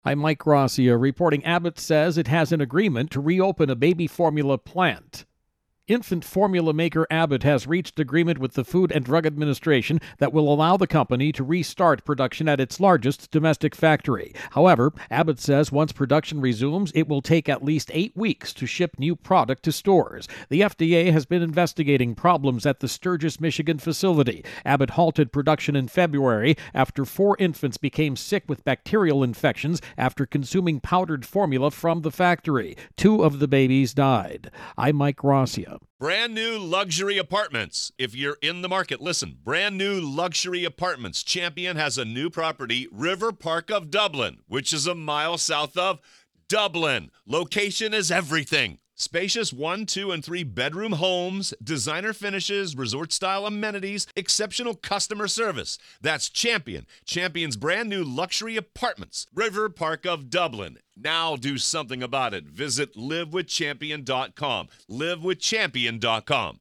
Baby-Formula-Shortage intro and voicer.